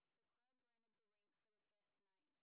sp28_street_snr30.wav